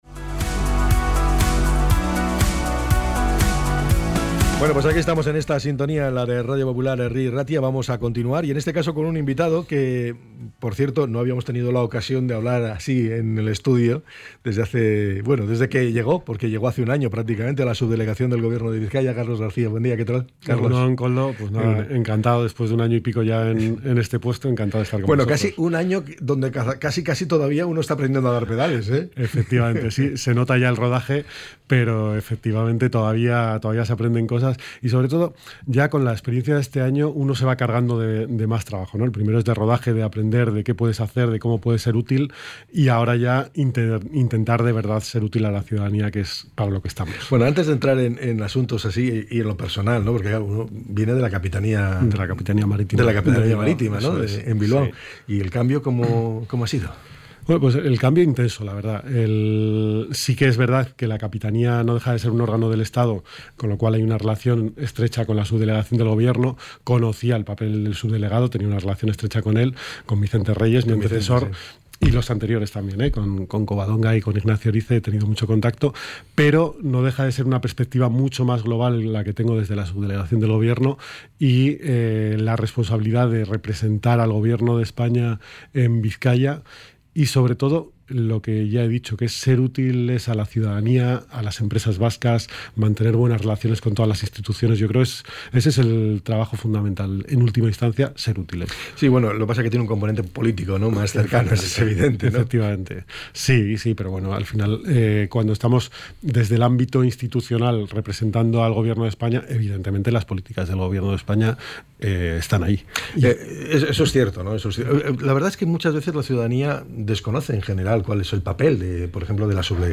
ENTREV.-SUBDELEGADO-GOBIERNO.mp3